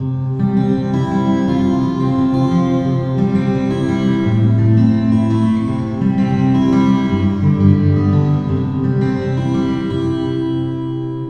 The first two are the sound samples with the impulses convolved with dry audio.
Synthetic Reverb
synthetic.wav